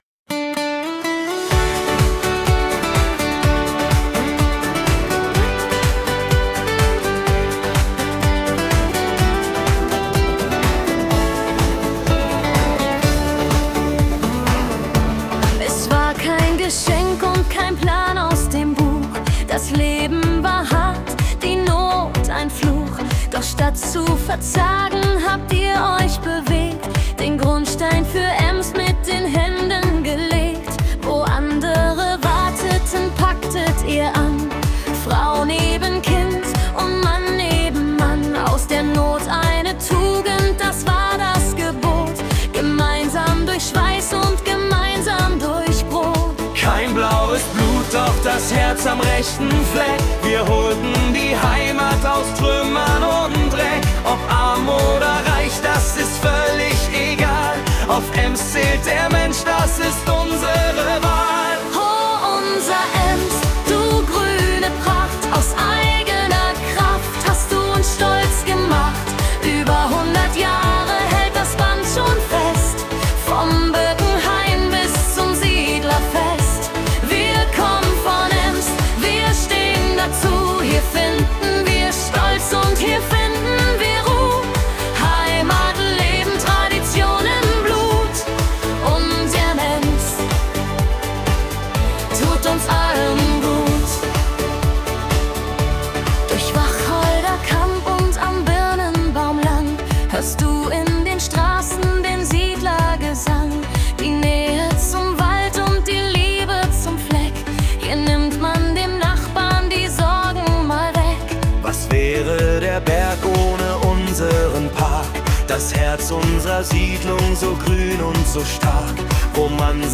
Siedlerbund-Lied (Helene Style)